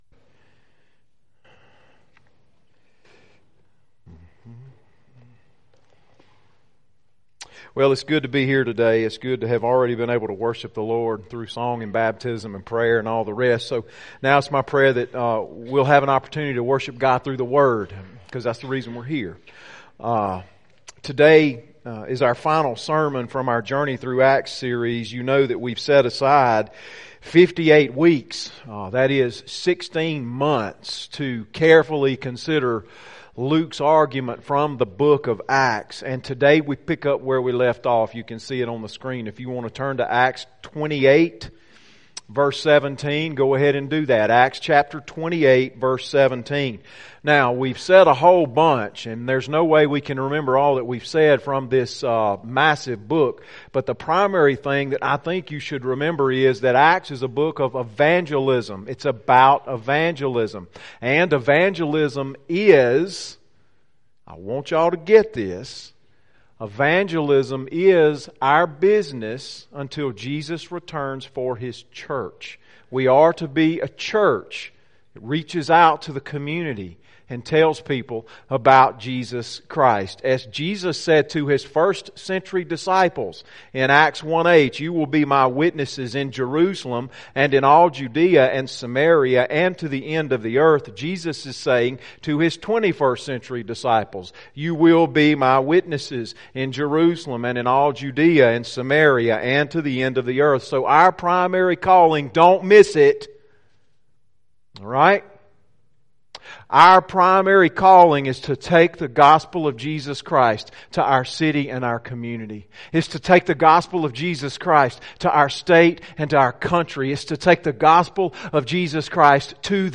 sermon-3-31-19.mp3